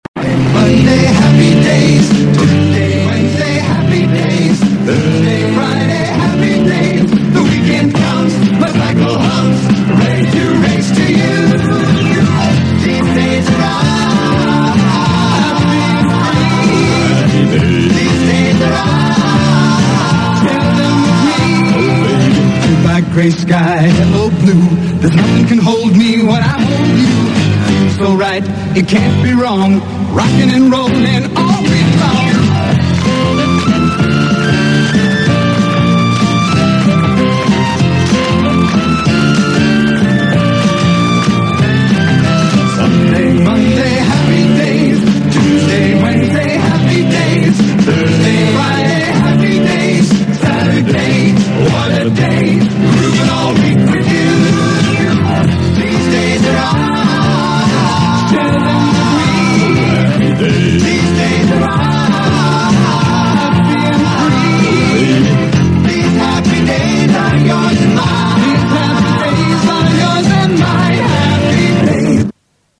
Extraits de l'émission de radio qui a duré de 1994 à 2001 sur Fun Radio sous des noms différents.